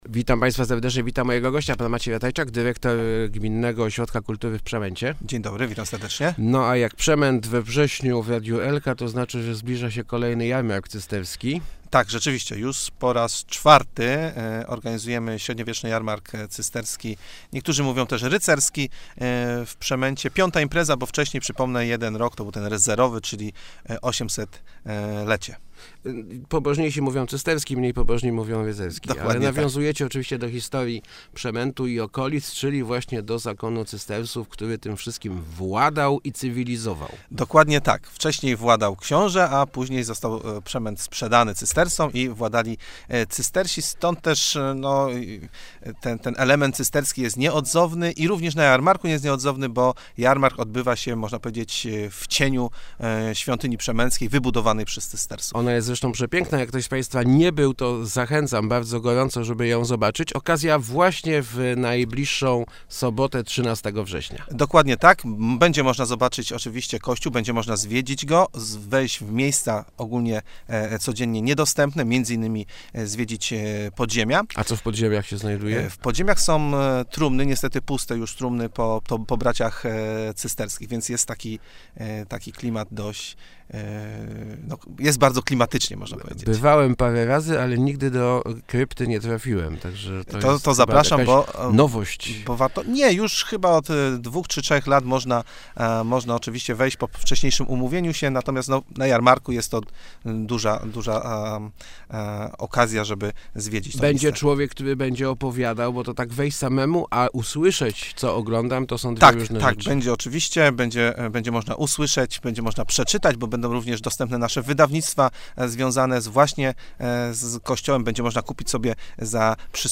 0908rozmowaelka.mp3